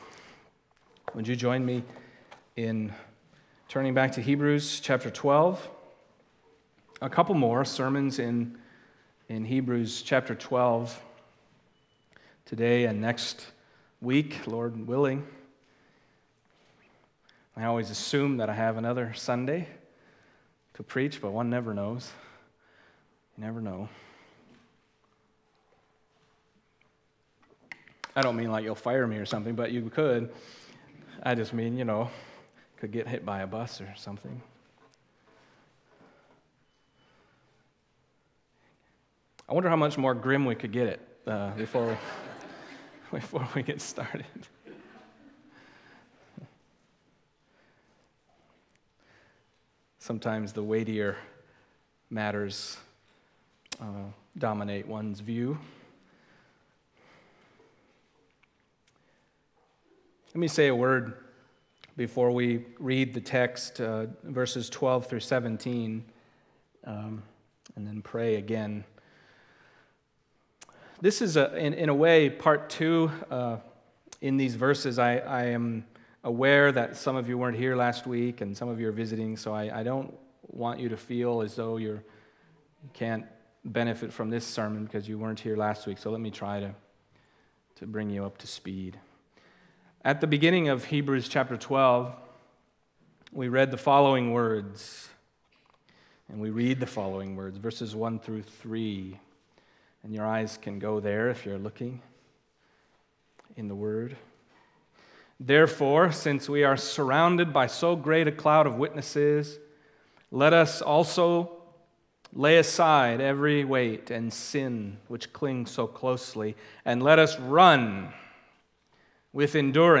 Passage: Hebrews 12:12-17 Service Type: Sunday Morning